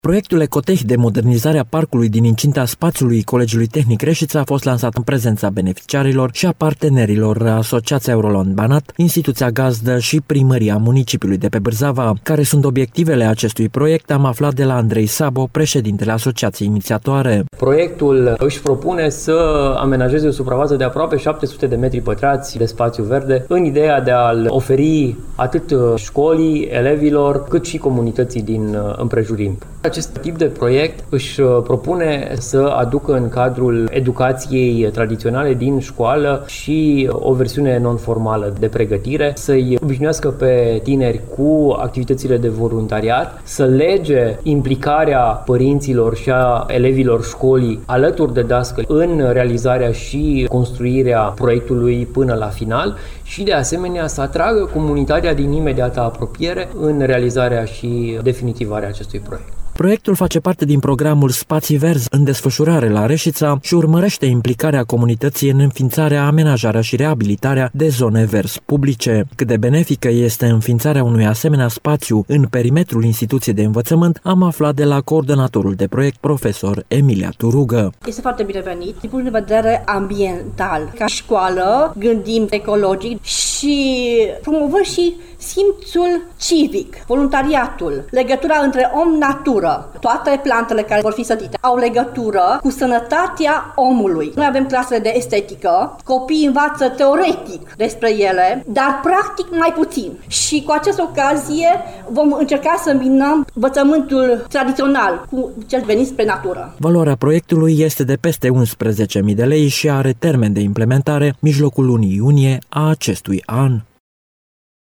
Proiectul “EcoTeh” de modernizare a parcului din incinta spaţiului Colegiului Tehnic Reşiţa a fost lansat astăzi în prezenţa beneficiarilor şi a partenerilor, Asociaţia Euroland Banat, instituţia gazdă şi Primăria municipiului de pe Bârzava. Proiectul face parte din programul “Spaţii verzi” în desfăşurare la Reşiţa şi urmăreşte implicarea comunităţii în înfiinţarea, amenajarea şi reabilitarea de spaţii verzi publice.